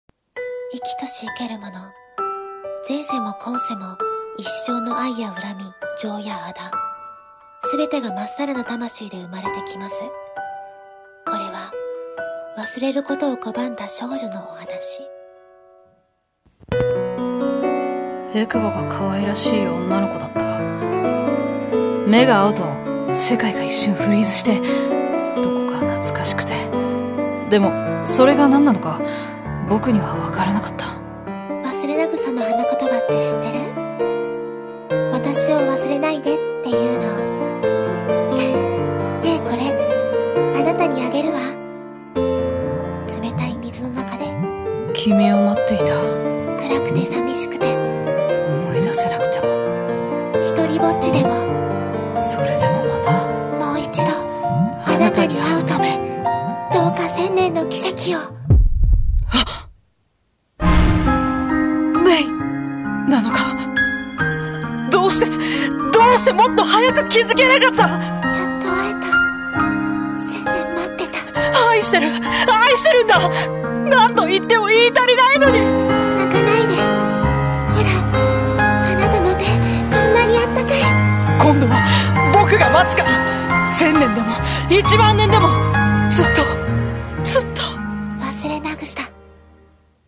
CM風声劇「勿忘草」